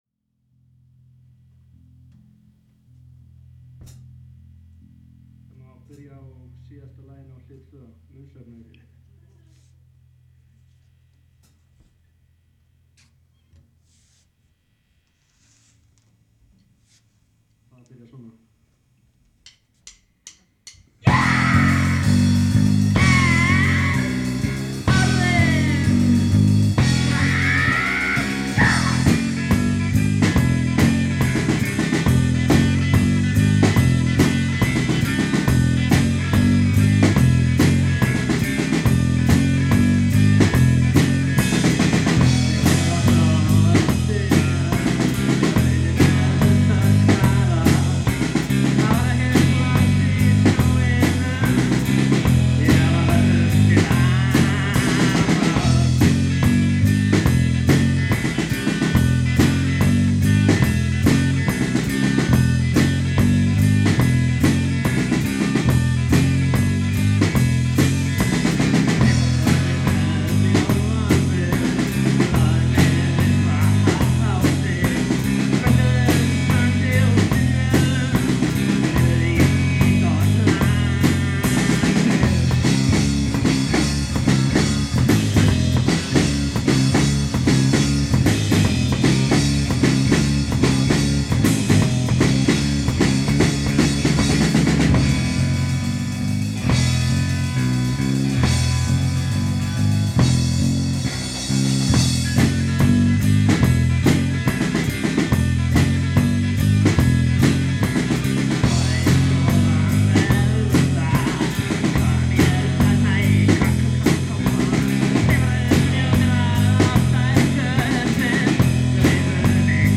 The punk-rockband
Vegna þessa eru margar þessara upptaka ekkert eyrnakonfekt.